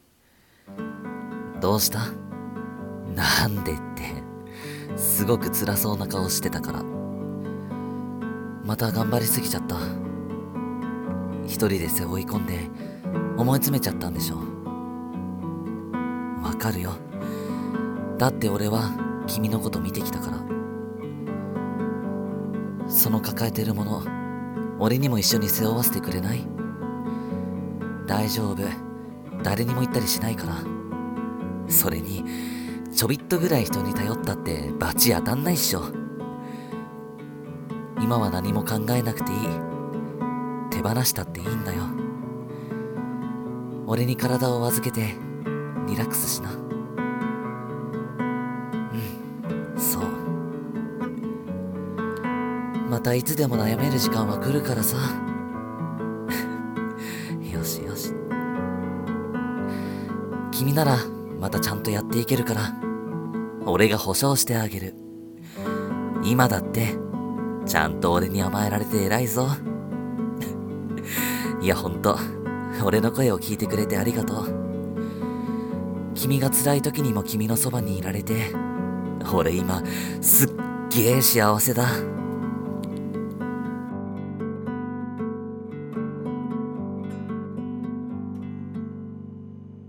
声劇 君をなぐさめ隊